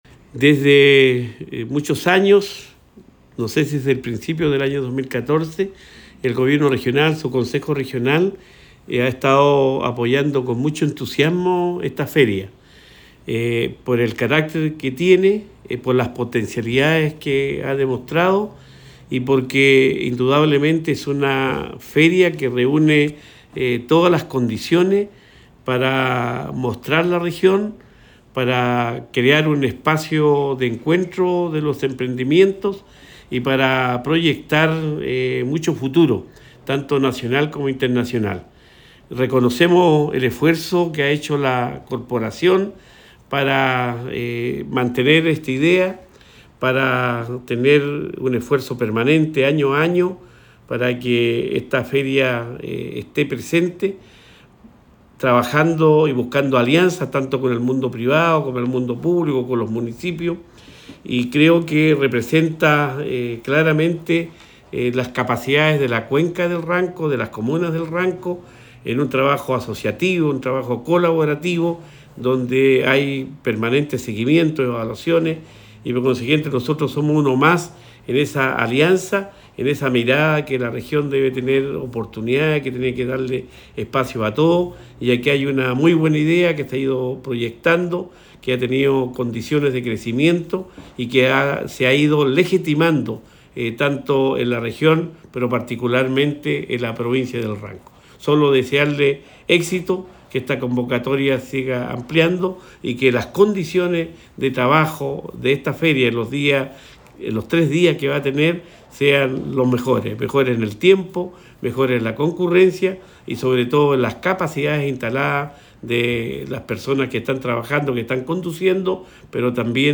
Cuña_Gobernador-Cuvertino_Ranco-Diseño.mp3